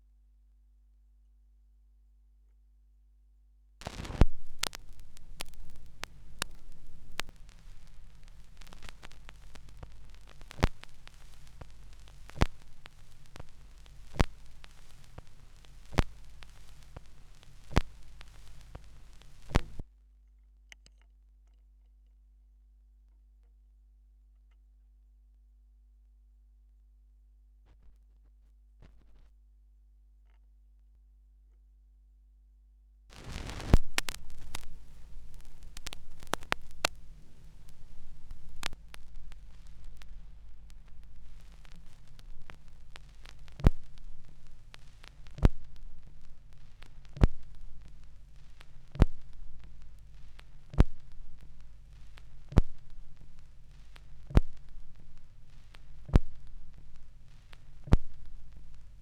2017 Schallplattengeräusche (3)
Leere Phrasen (Leerstellen auf Schallplatten, die digitalisiert werden.)
Digitalisierung: Thorens TD 165 Special, Orthophon Pro System, Tascam HD-P2 (24 Bit, 48 KHz), Audacity